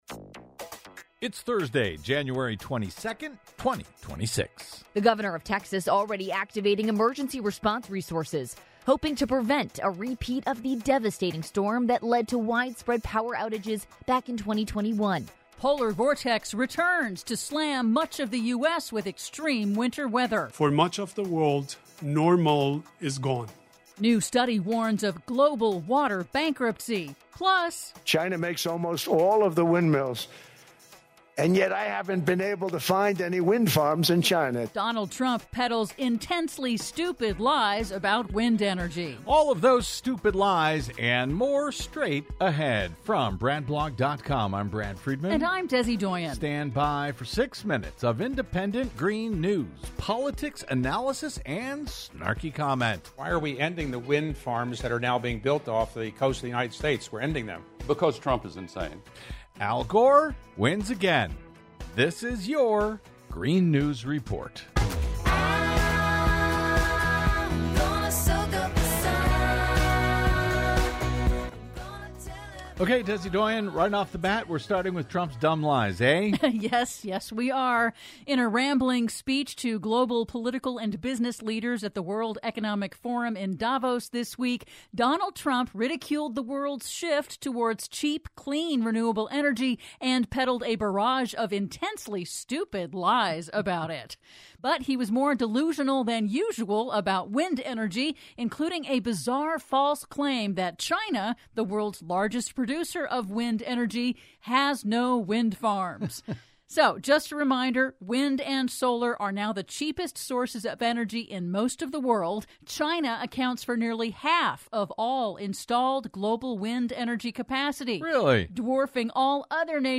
GNR's now celebrating 16 YEARS of independent green news, politics, analysis, snarky comment and connecting climate change dots over your public airwaves!